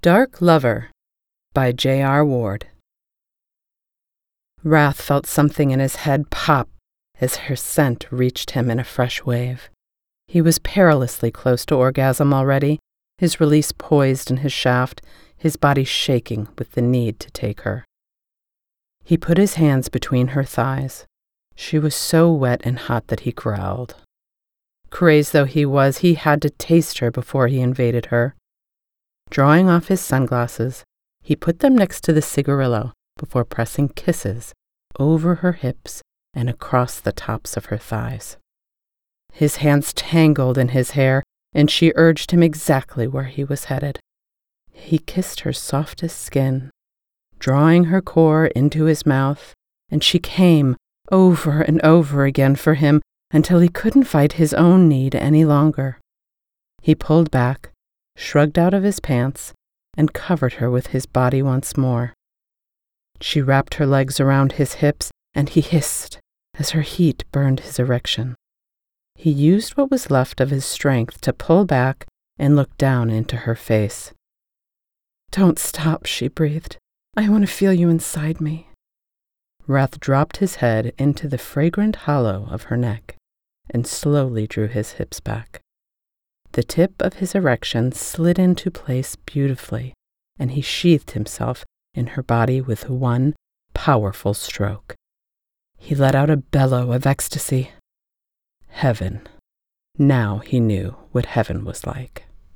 Broadcast Quality Voiceover Talent and Certified Audio Engineer
Romance
Working from my broadcast-quality home studio is not just my profession—it’s my joy.